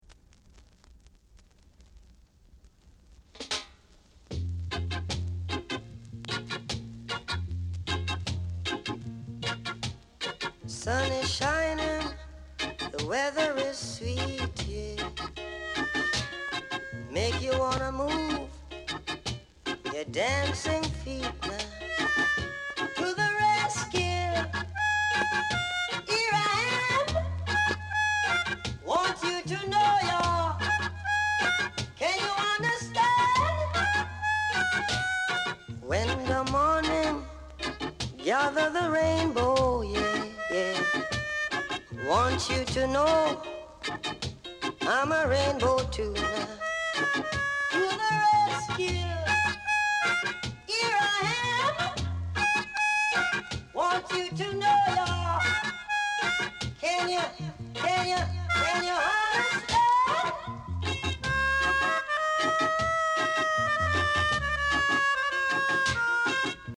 Reggae Vocal Group
Rare UK press! great reggae vocal w-sider!